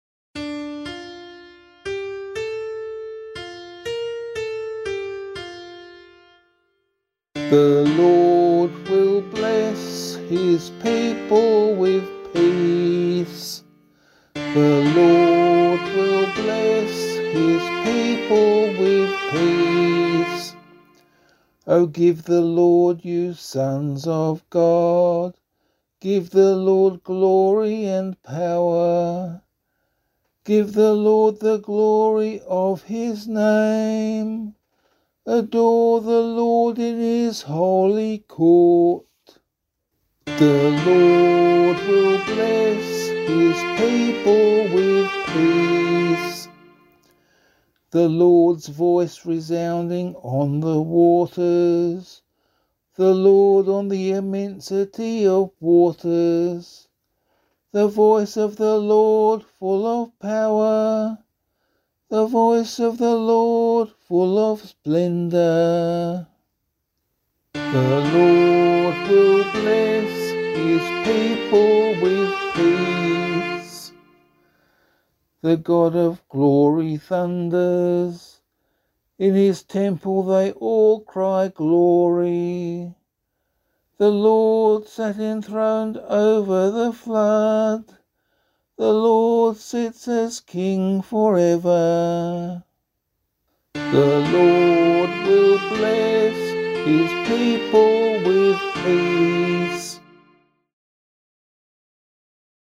011 Baptism of the Lord Psalm A [LiturgyShare 1 - Oz] - vocal.mp3